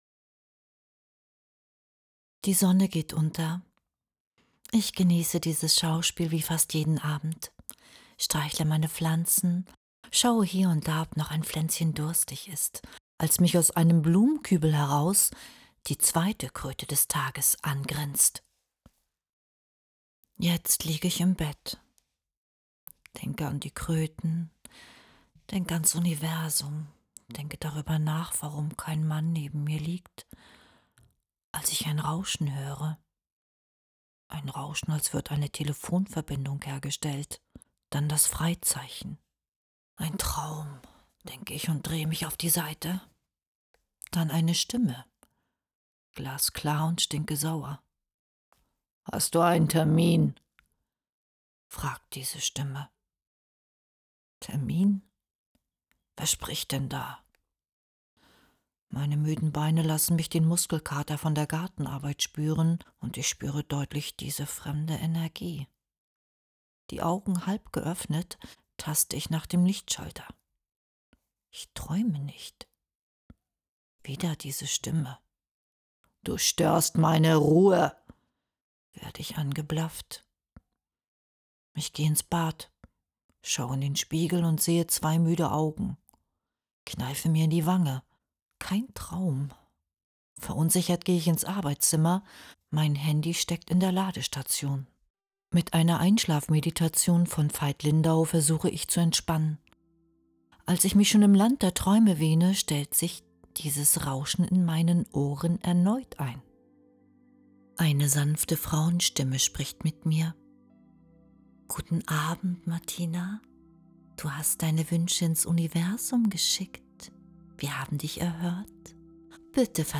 Du bist auf der Suche nach einer weiblichen angenehmen Stimme?